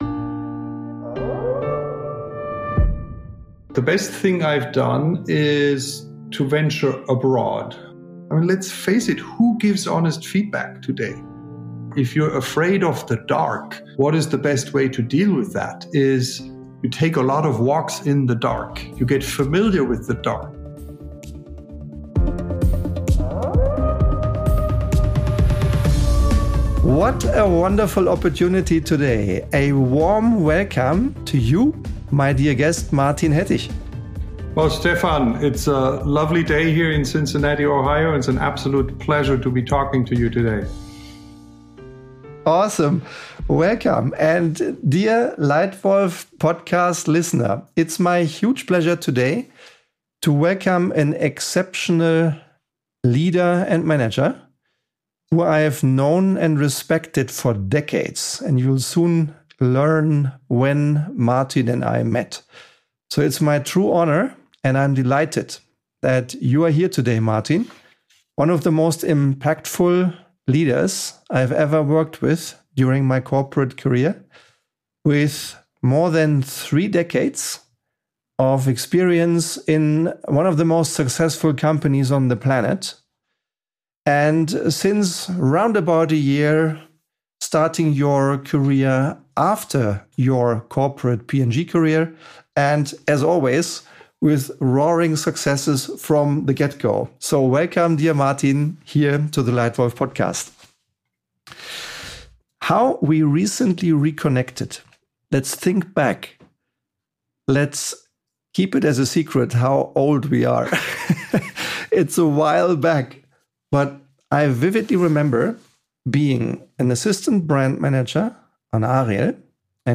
The conversation explores entrepreneurial curiosity, critical thinking, and the discipline of continuous reinvention.